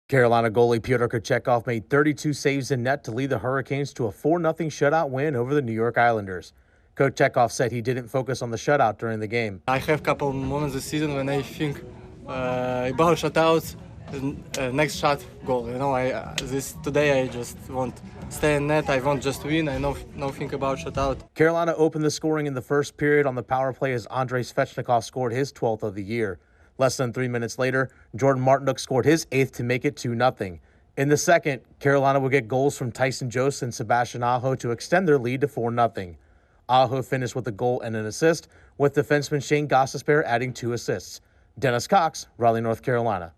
The Huhrricanes take advantage of the punchless Islanders. Correspondent